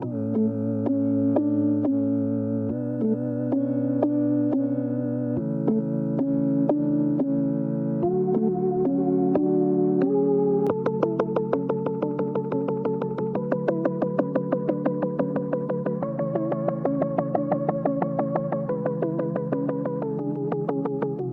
lead2